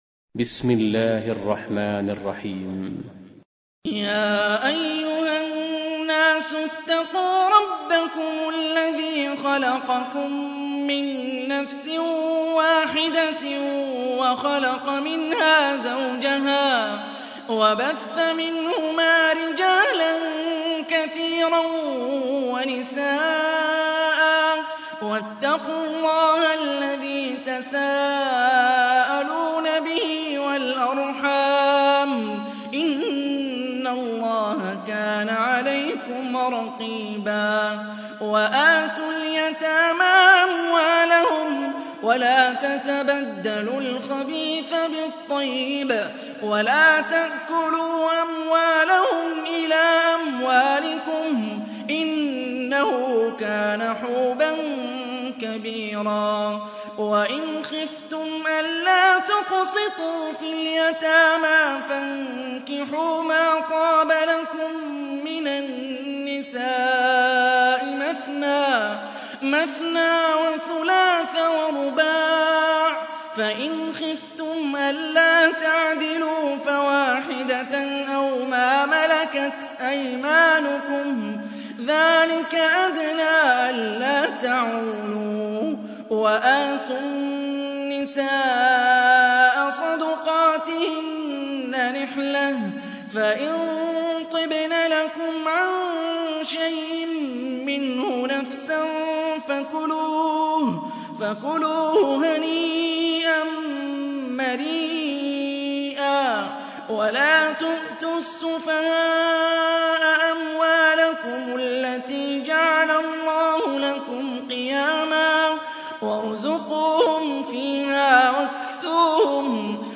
Surah Repeating تكرار السورة Download Surah حمّل السورة Reciting Murattalah Audio for 4. Surah An-Nis�' سورة النساء N.B *Surah Includes Al-Basmalah Reciters Sequents تتابع التلاوات Reciters Repeats تكرار التلاوات